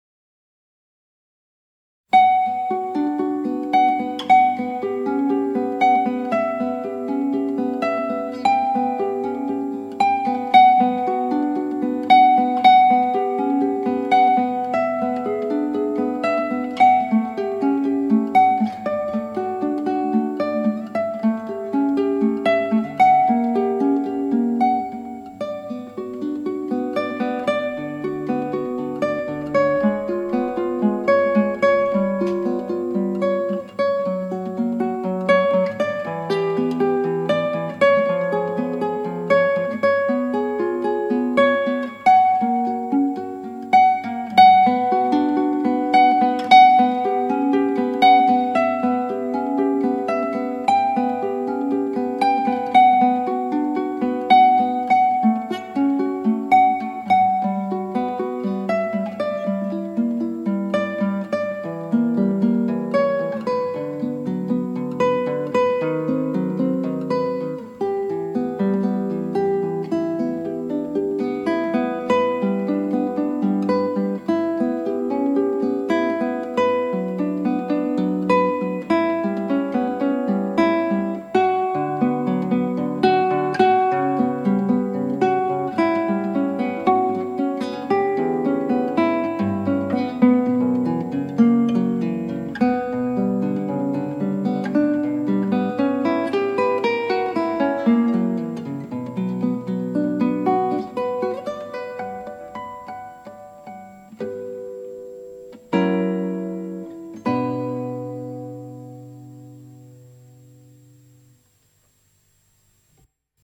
クラシックギター　ストリーミング　コンサート
大聖堂のトップをつとめるこのプレリュード、ひたすら物悲しい曲です。